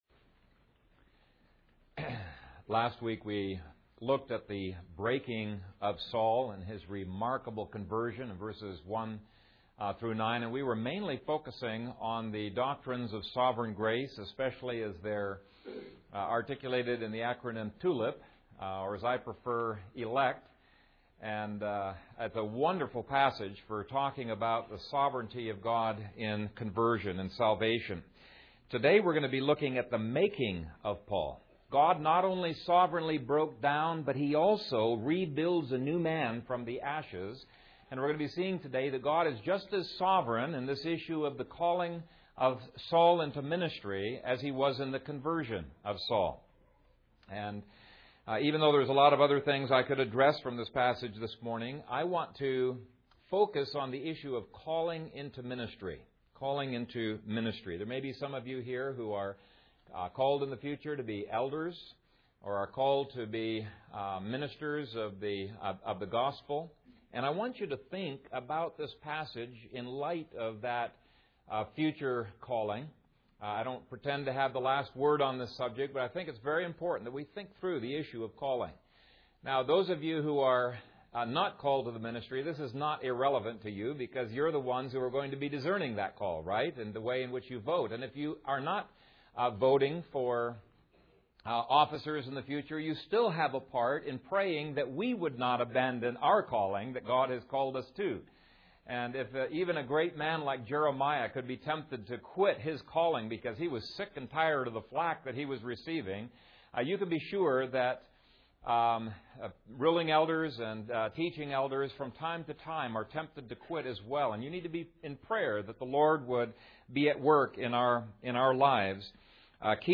The Making of Paul | SermonAudio Broadcaster is Live View the Live Stream Share this sermon Disabled by adblocker Copy URL Copied!